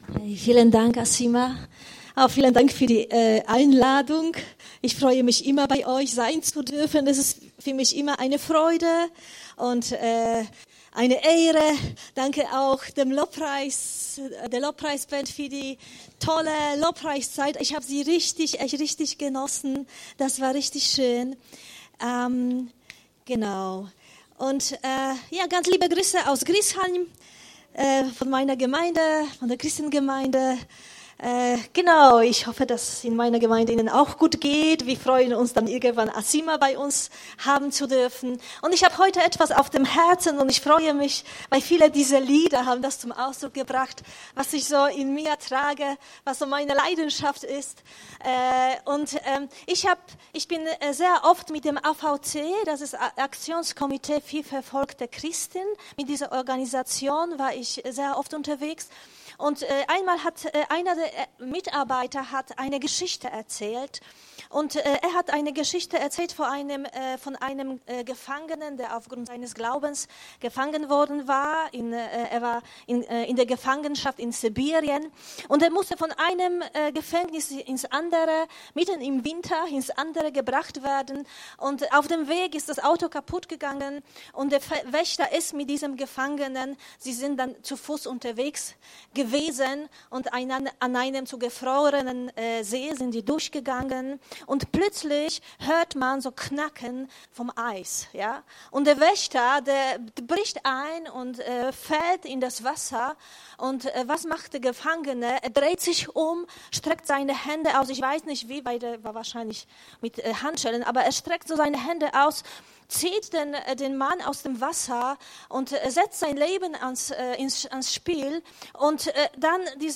Eine allgemeine Predigt
Aktuelle Predigten aus unseren Gottesdiensten und Veranstaltungen